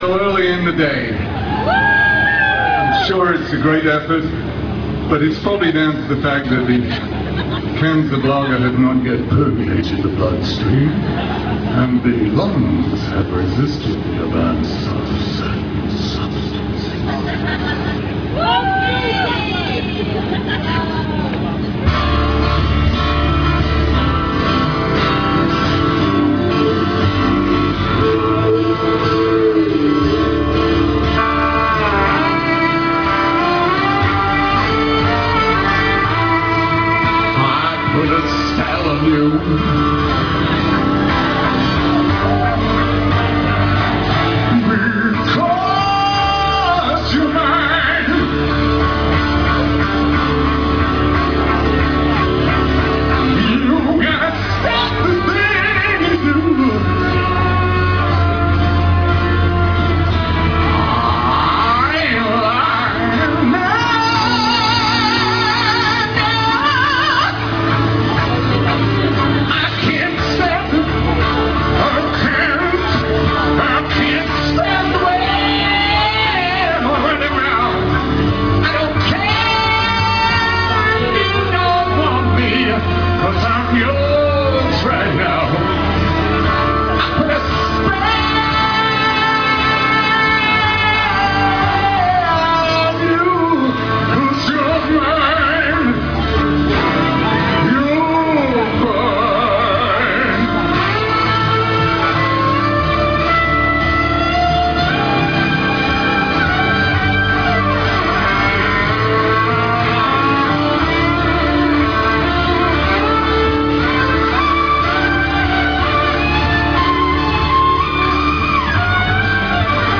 With a Fantastic Violin Solo